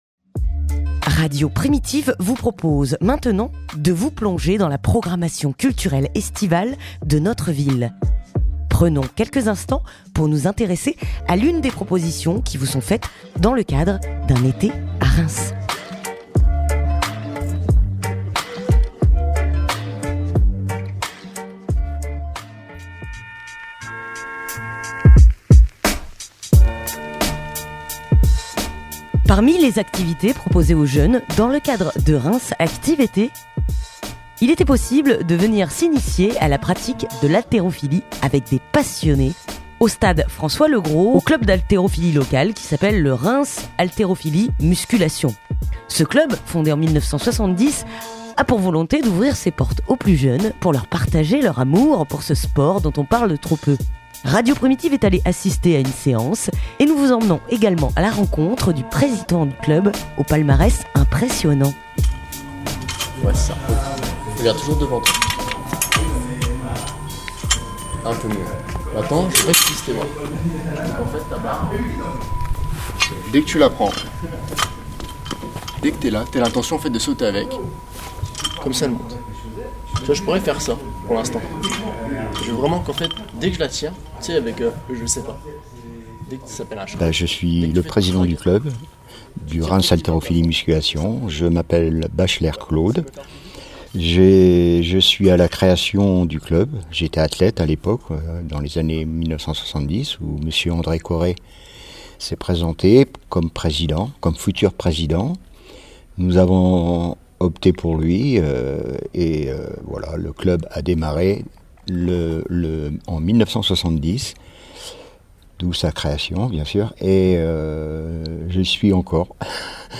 Reportage au Gymnase François Legros (11:11)